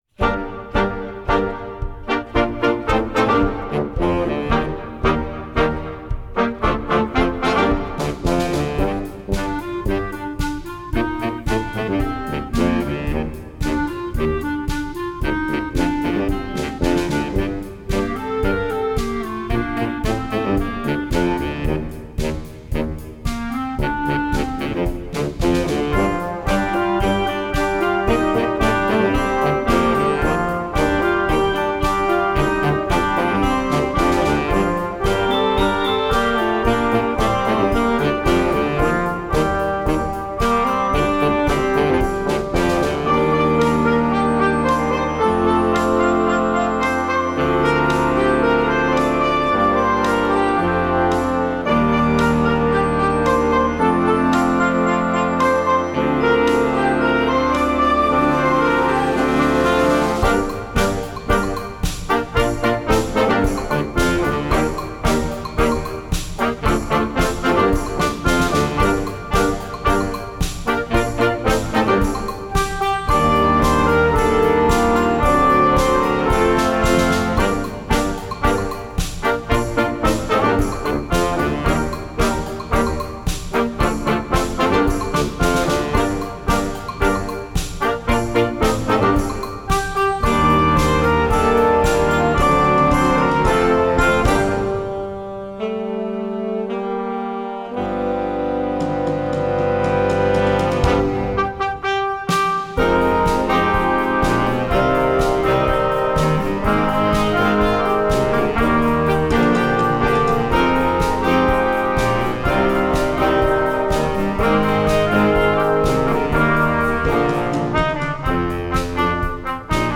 Gattung: für Jugendblasorchester
Besetzung: Blasorchester